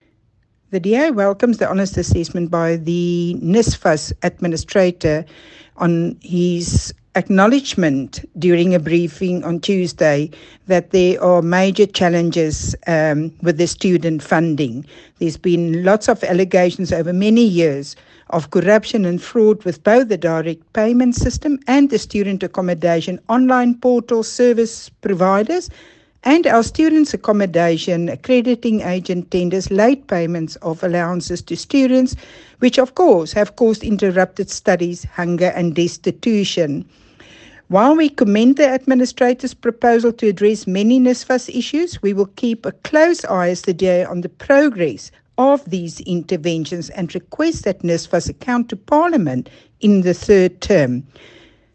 soundbite by Desiree van der Walt MP